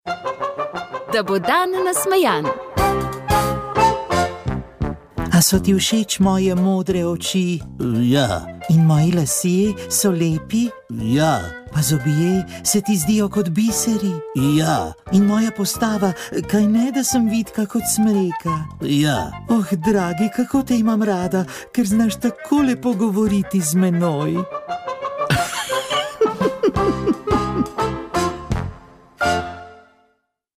Molile so redovnice - Sestre sv. Križa.